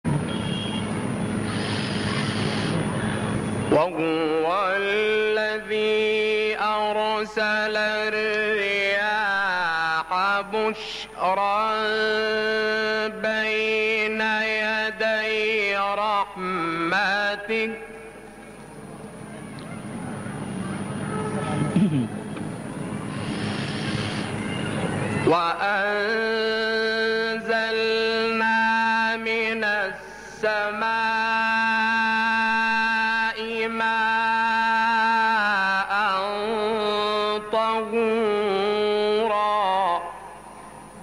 تلاوت سوره فرقان با صوت «شعبان صیاد»
این تلاوت 40 دقیقه‌ای در سال 1980 میلادی در قصر عابدین مصر اجرا شده و شعبان صیاد به تلاوت آیات 45 تا آخر سوره مبارکه فرقان به روایت حفص از عاصم کوفی پرداخته است.